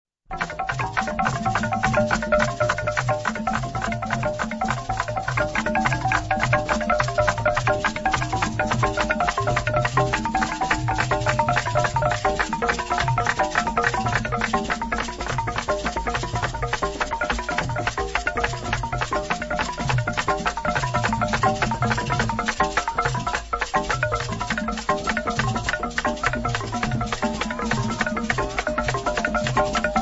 Fete bamileke